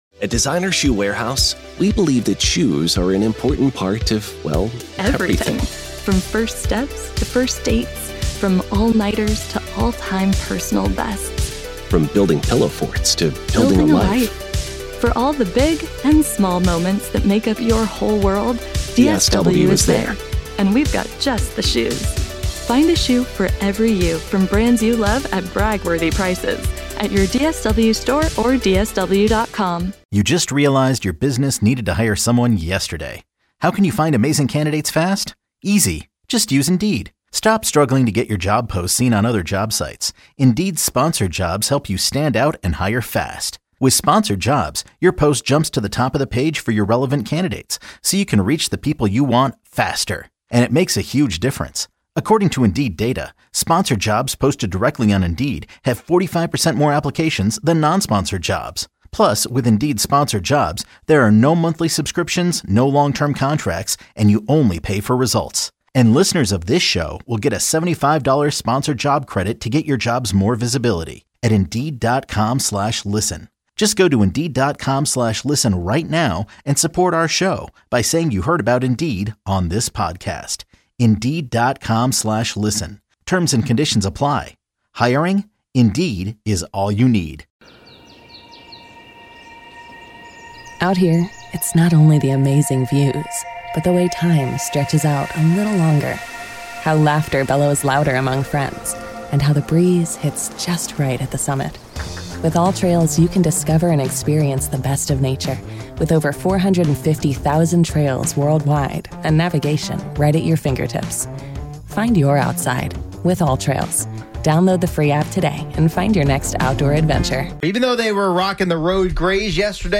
We also heard all the sounds of the Mets' loss to the Guardians again.